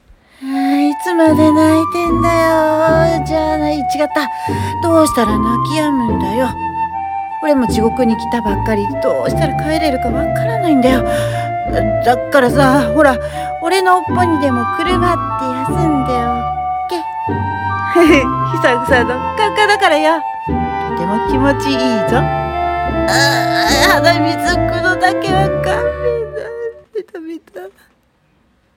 】1人声劇『妖狐の尻尾』 演者:あなた様 nanaRepeat